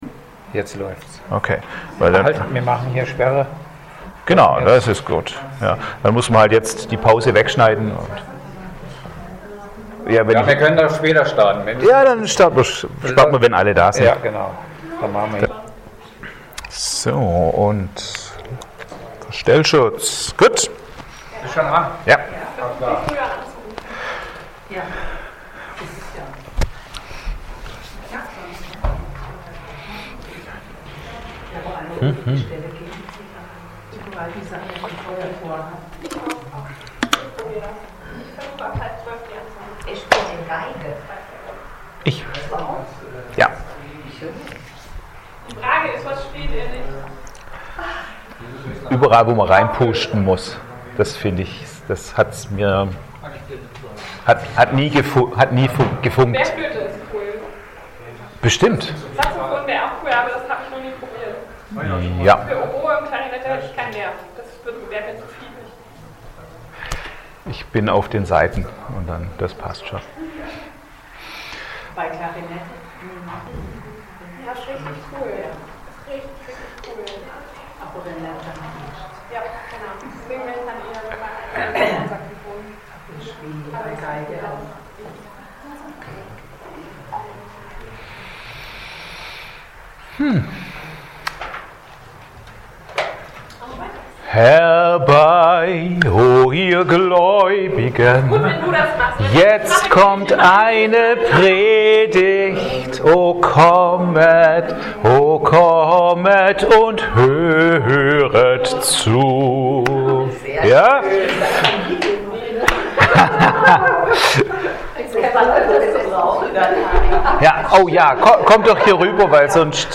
Dienstart: Externe Prediger